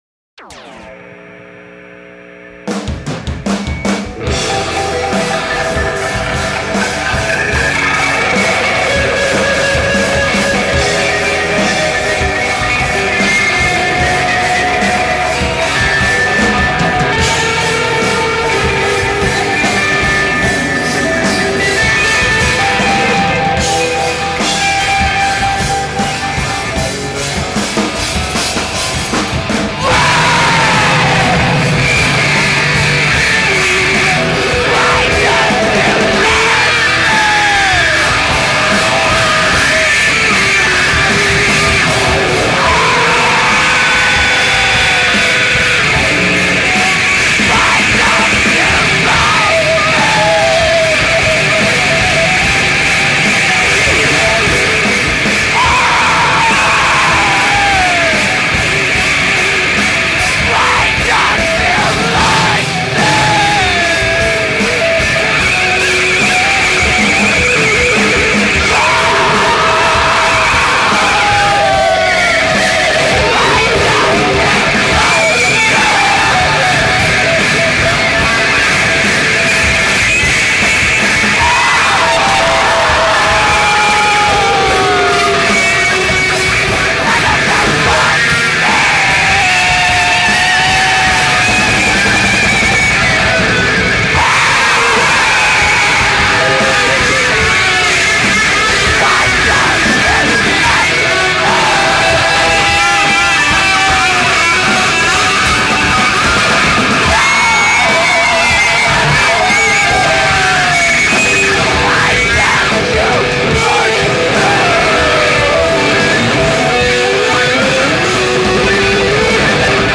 So savage.